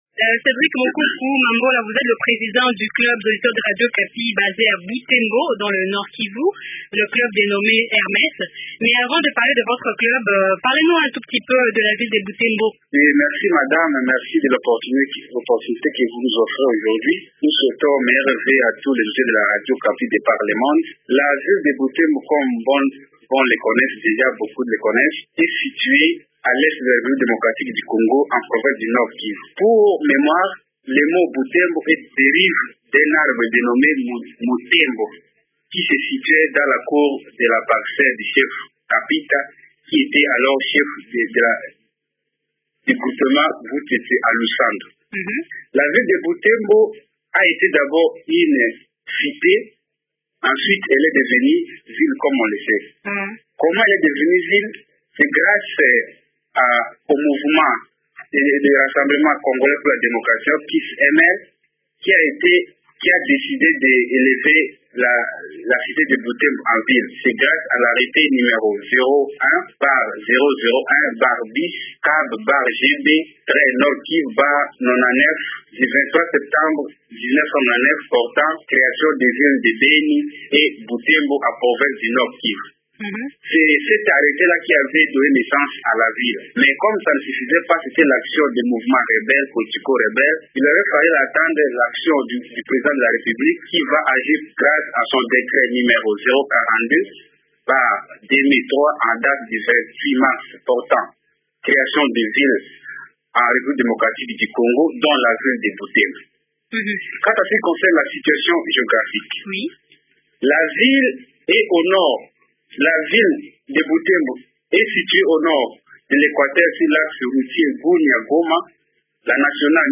Le Caro Hermes compte à ce jour une centaine membres, soucieux de partager l’idéal de radio Okapi. Pour cela, le club multiplie des réunions et conférences dans les différents lieux publics de Butembo afin de sensibiliser la population locale. Des détails dans cet entretien